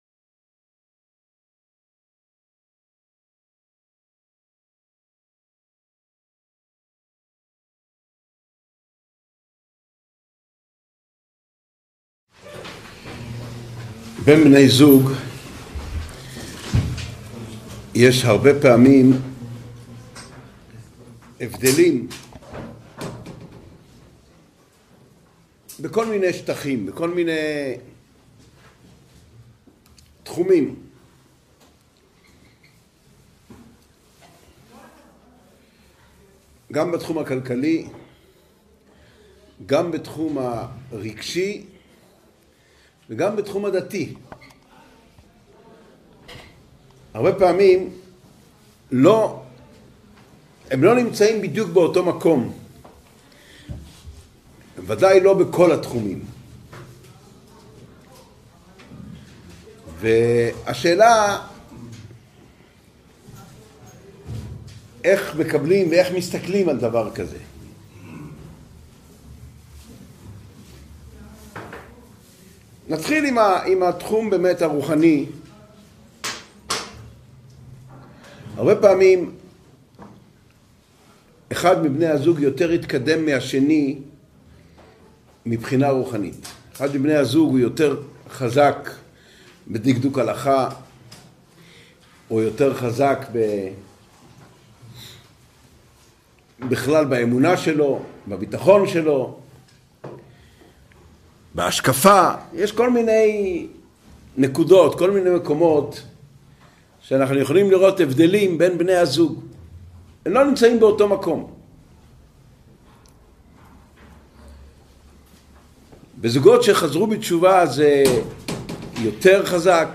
Урок № 6.